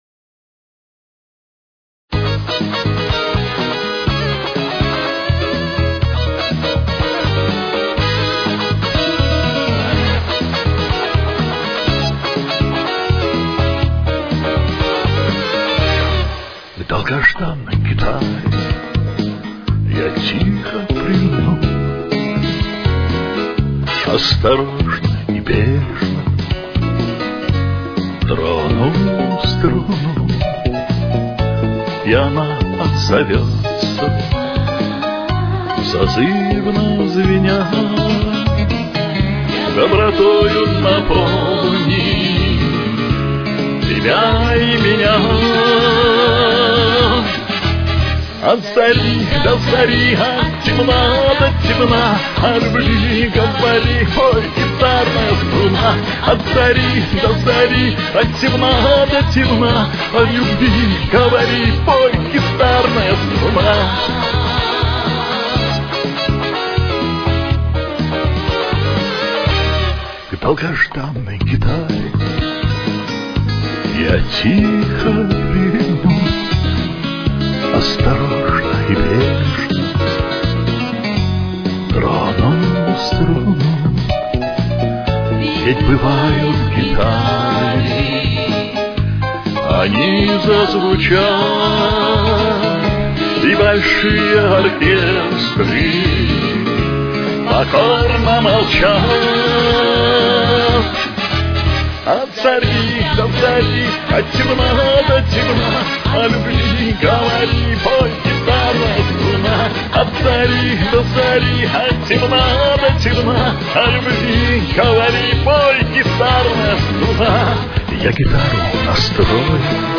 с очень низким качеством (16 – 32 кБит/с)
Темп: 126.